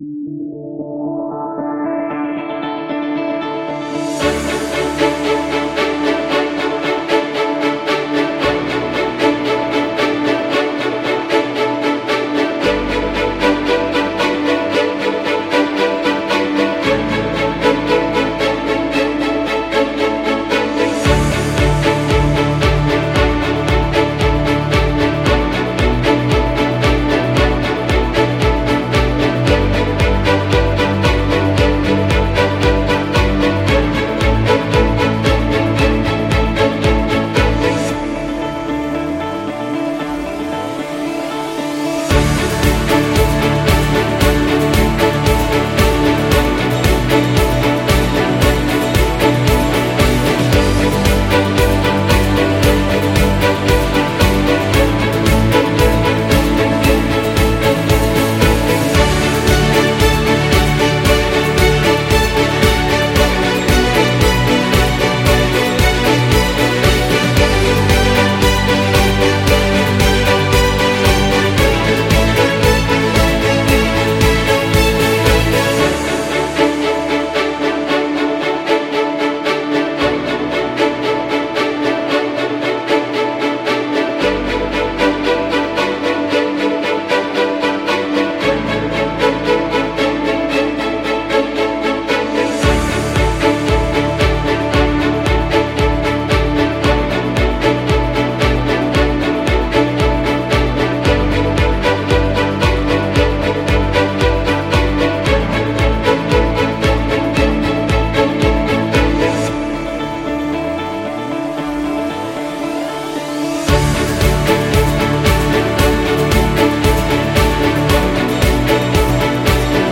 Corporate